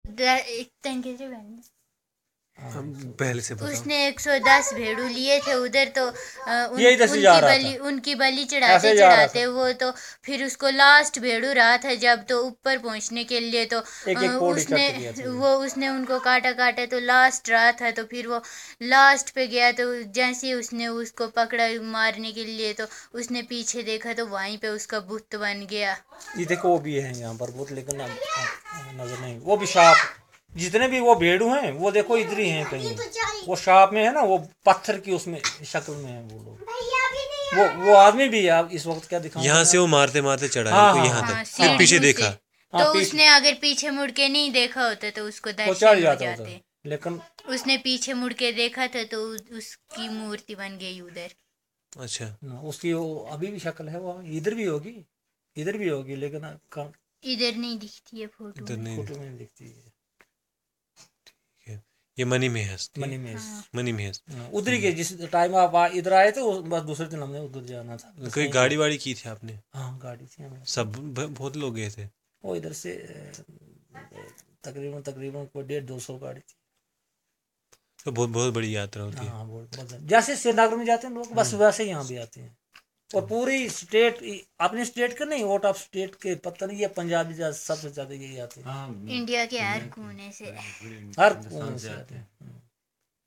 Conversation about ghost